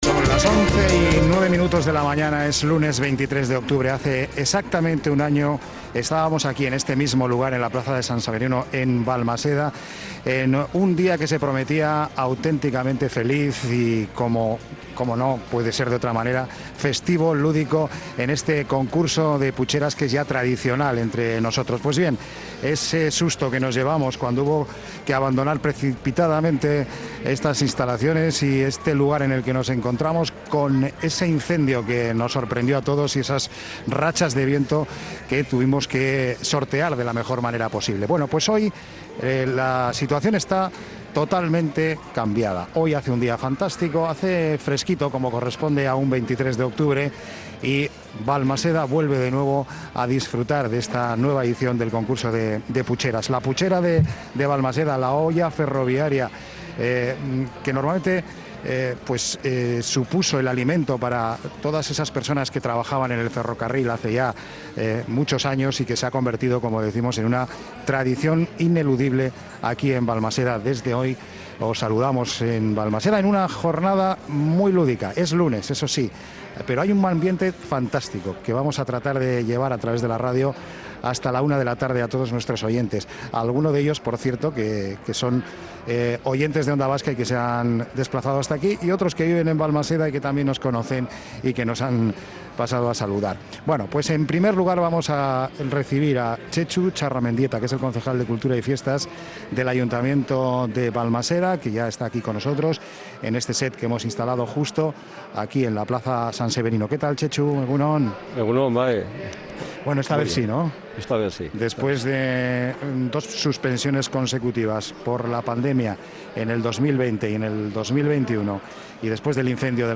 El concejal de cultura y fiestas Txetxu Txarramendieta reconoce que "sin el concurso de putxeras no sería San Severino". Onda Vasca lo ha comprobado con una programación especial en el día grande de las fiestas